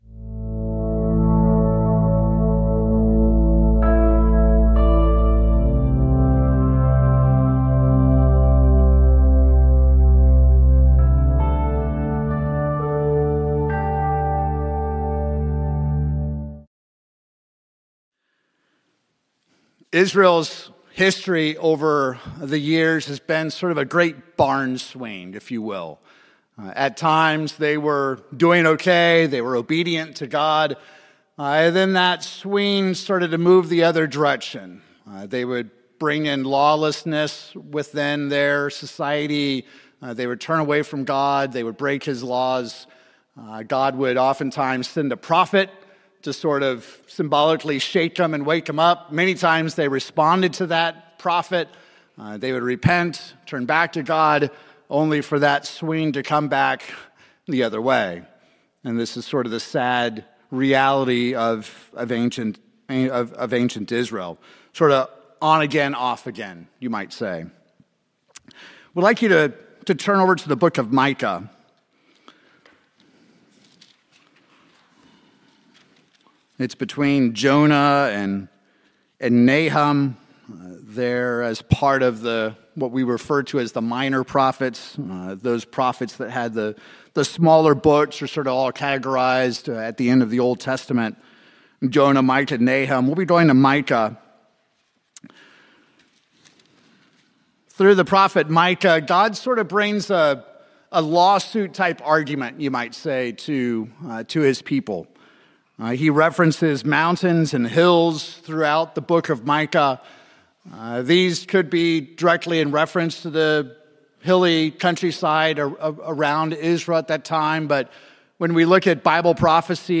The prophet Micah, along with Jesus Christ, give us three things that God requires of His people. In this sermon, we will see that justice, mercy, and faith are not distinct and separate concepts, but rather cohesive and interconnected.